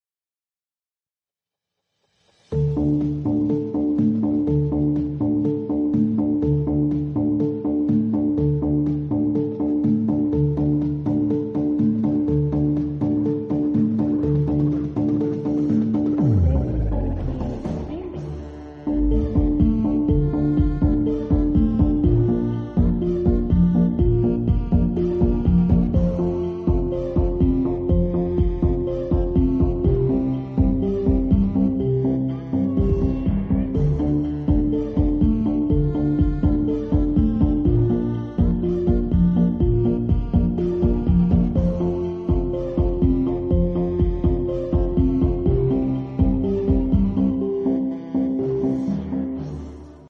(Reverb+3D Sound)